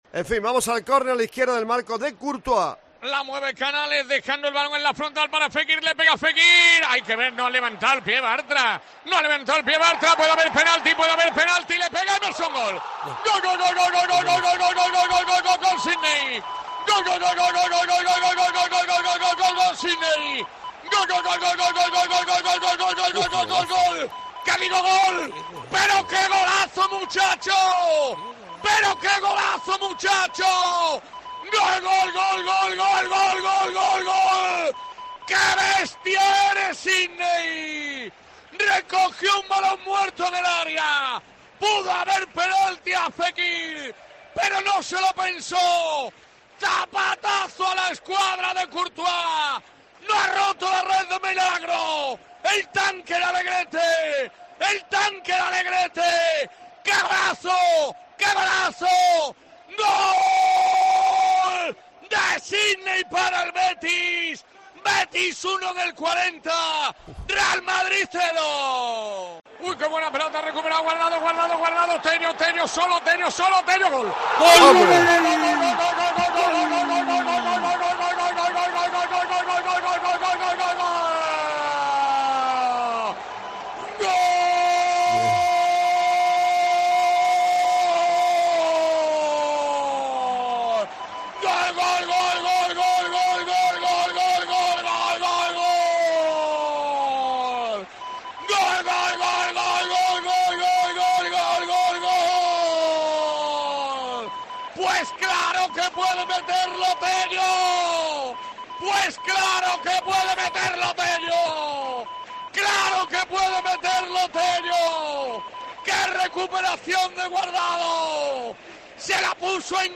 Real Betis-Real Madrid (2-1): Así sonaron los goles del triunfo verdiblanco en Deportes Cope Sevilla